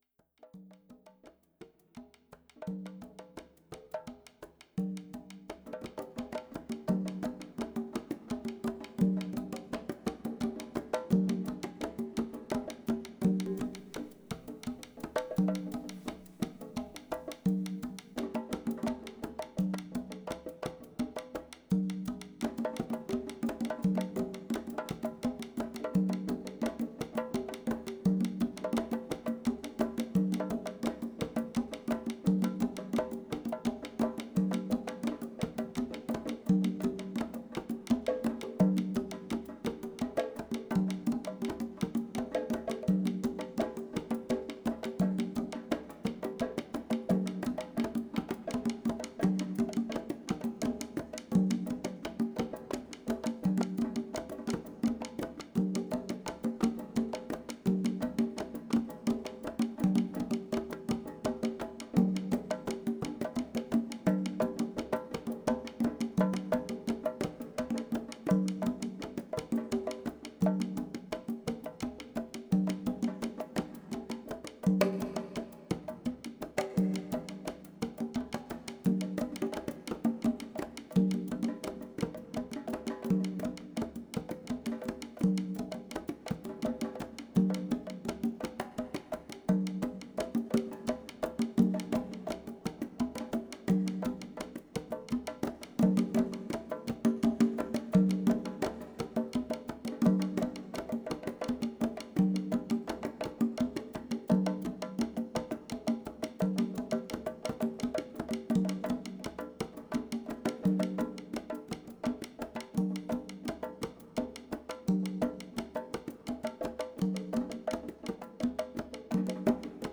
bata and tubano drums